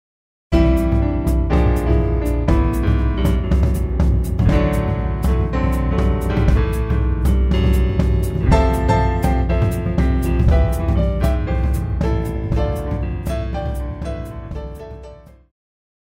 爵士
套鼓(架子鼓)
乐团
演奏曲
融合爵士
独奏与伴奏
有节拍器